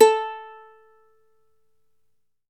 Index of /90_sSampleCDs/E-MU Formula 4000 Series Vol. 4 – Earth Tones/Default Folder/Ukelele
UKE A3-R.wav